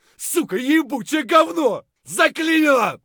gun_jam_7.ogg